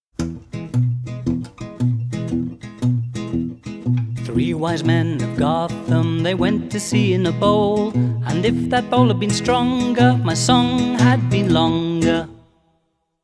Counting Songs